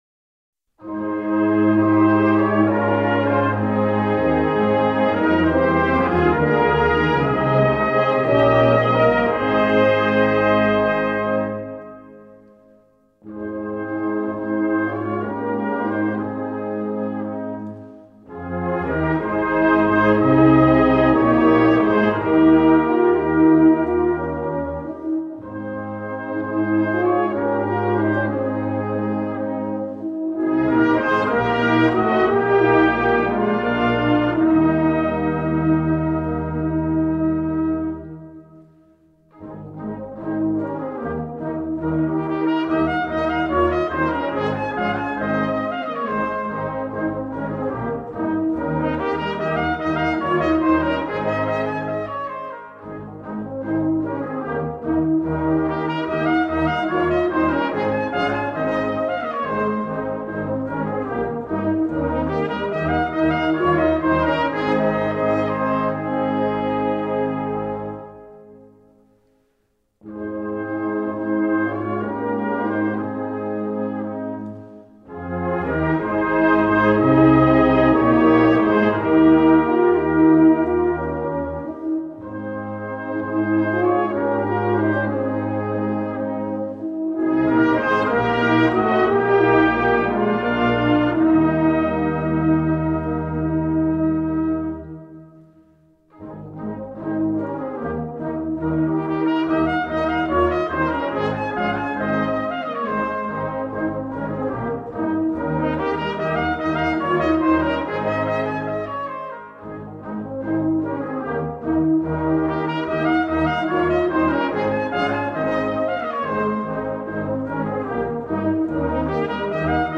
Fanfare Amicale-Vudallaz d’Albeuve/Enney